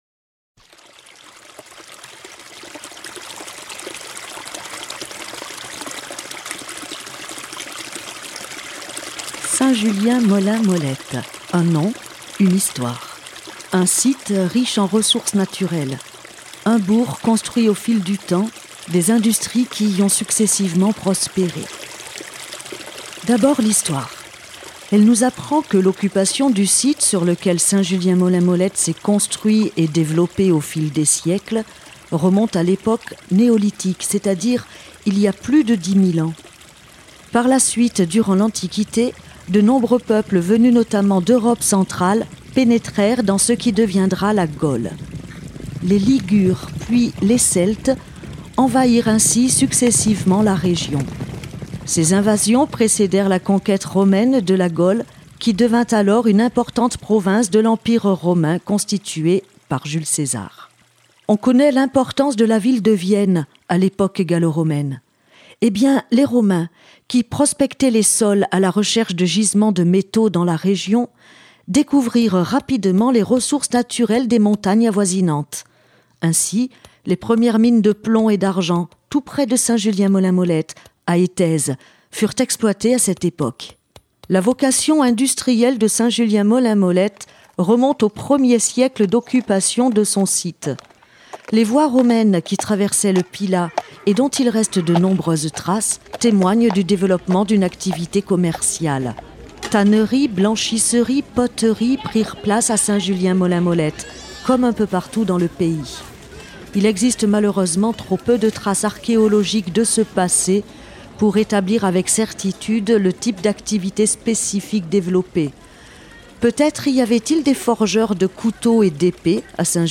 « Soie disant », recueil audio de témoignages, réalisation Radio d’ici
L’association Patrimoine Piraillon remercie la radio associative locale, Radio d’ici, de partager ici son travail réalisé autour des usines de Saint-Julien-Molin-Molette à l’occasion des Journées « Patrimoine de Pays » les 14 et 15 juin 2008.